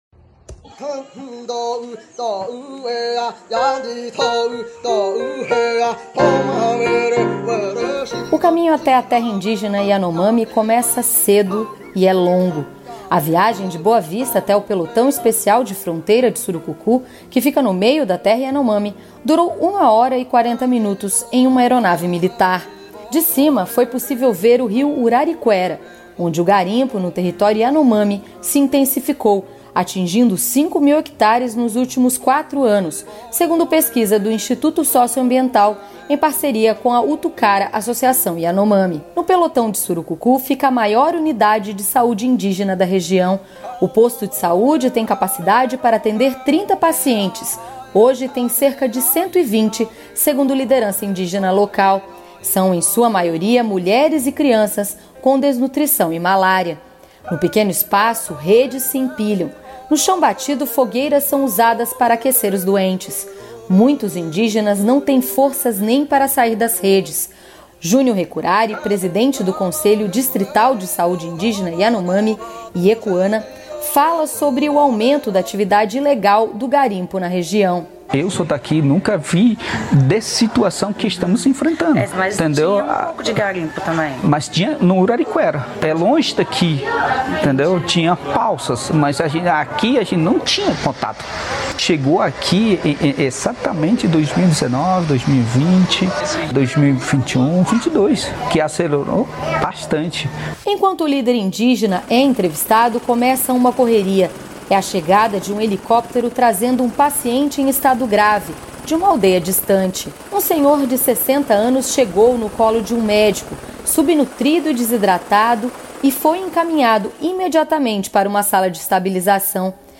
Enquanto o líder indígena é entrevistado, começa uma correria.